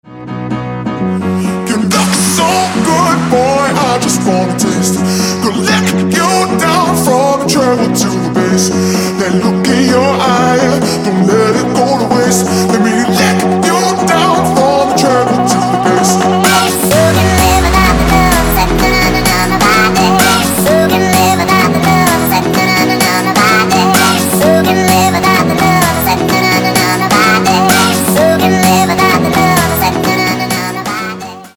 Метки: club, dance, Electronic, мужской вокал, vocal,